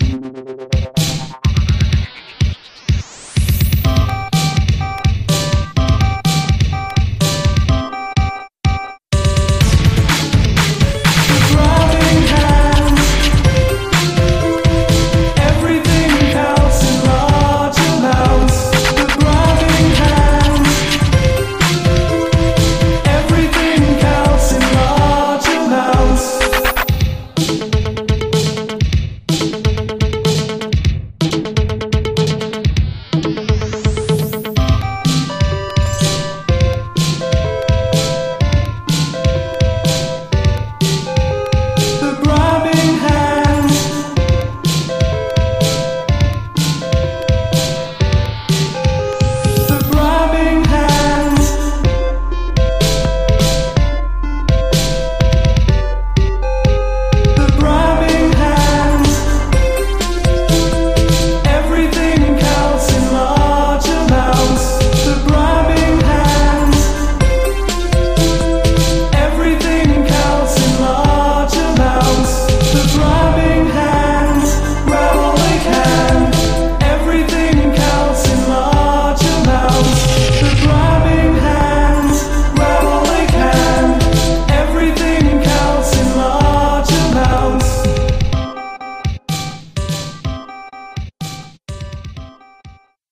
125 bpm
Clean Version